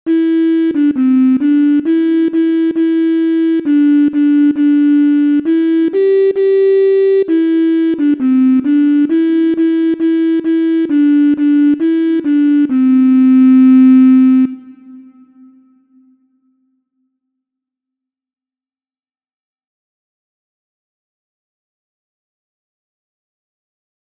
Introducing the dotted eighth and sixteenth syncopation
• Origin: USA – 19th-century Nursery Rhyme
• Key: C Major
• Time: 2/4
• Form: ABAC